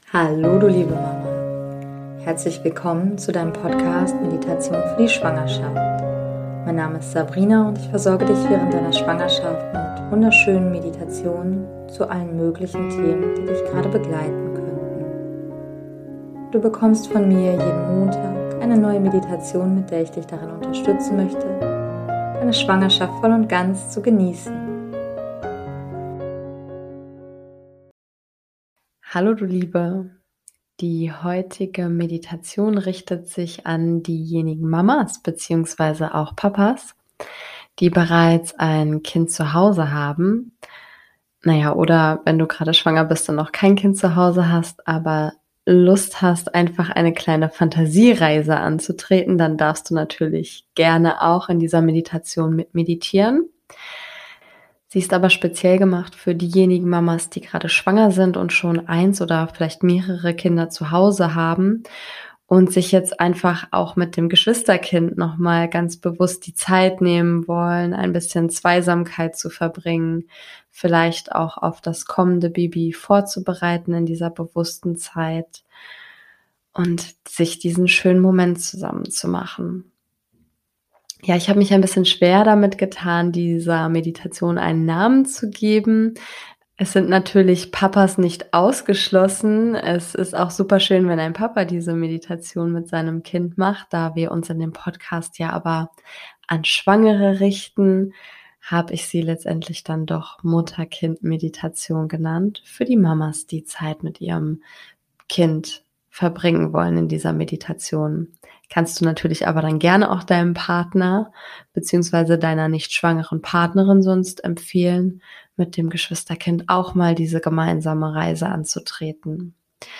#046 - Mutter Kind Meditation - Eine Phantasiereise mit dem Geschwisterkind ~ Meditationen für die Schwangerschaft und Geburt - mama.namaste Podcast